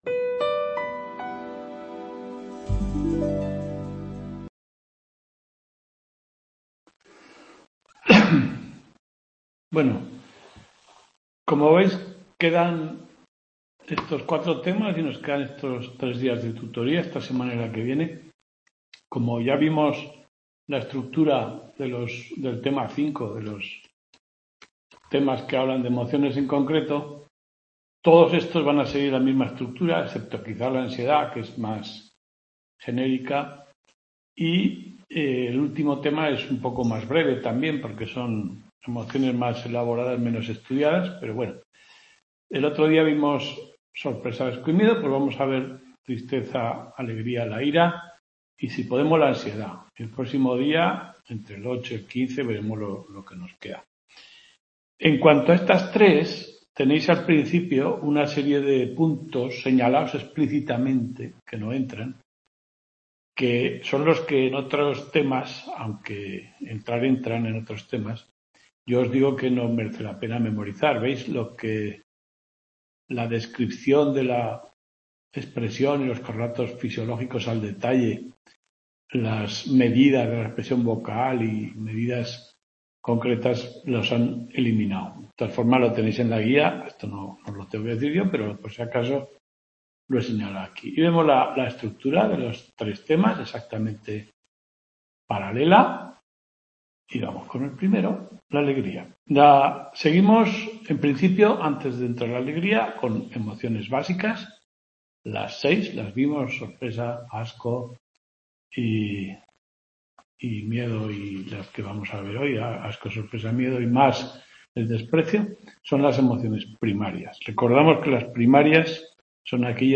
Tudela 06/05/2025 Description Tutoría.